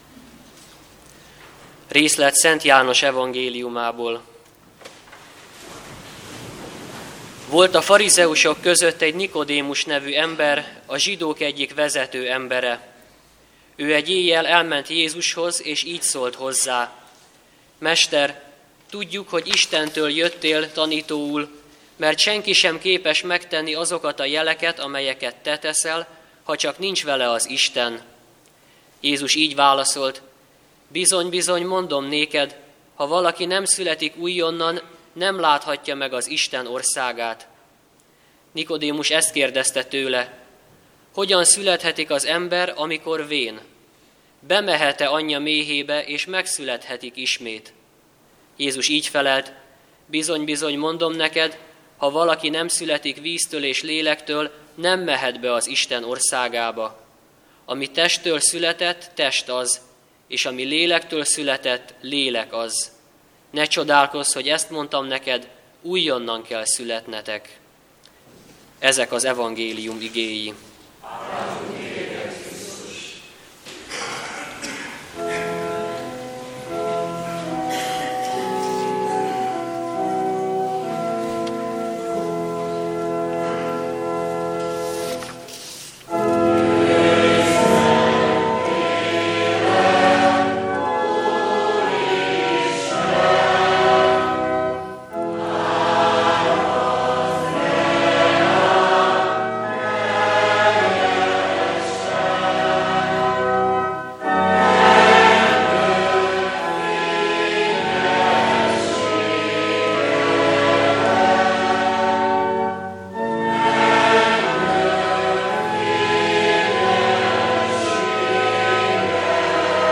Ökumenikus imahét.